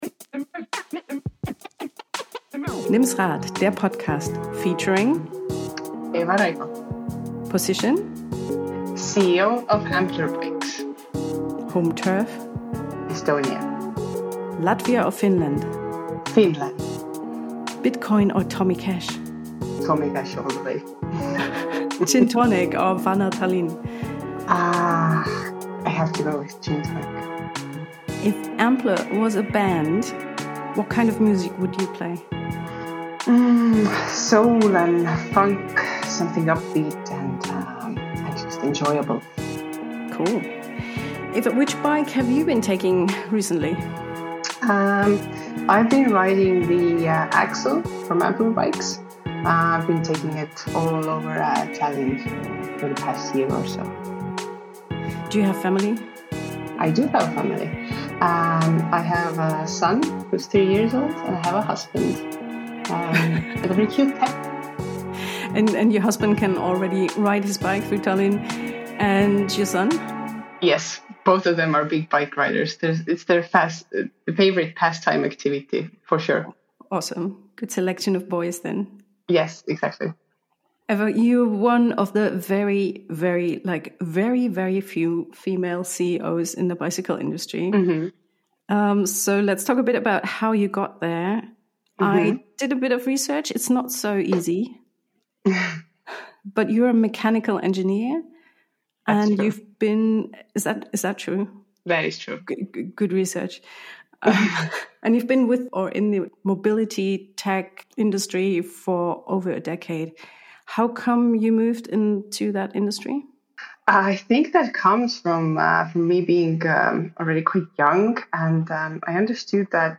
Im Gespräch geht es um nicht weniger als einen Paradigmenwechsel: das neue Ampler Nova mit USB-C-Ladefunktion, ein klares Statement für Nutzerfreundlichkeit und Simplifizierung im urbanen Radverkehr.